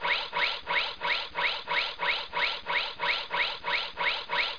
1 channel
parts_sound_3pwlegsrun.mp3